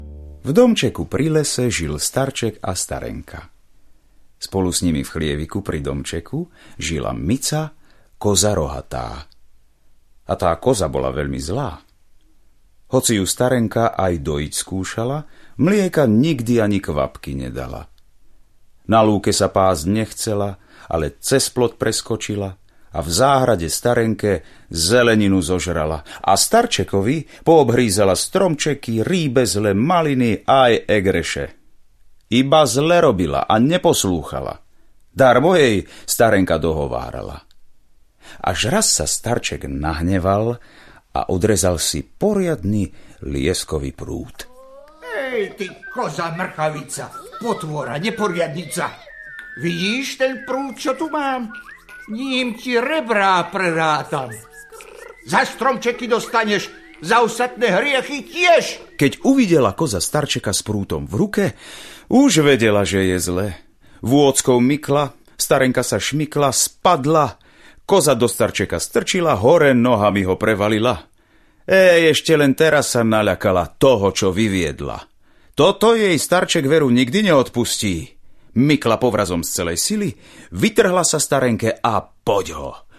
Koza rohatá a jež audiokniha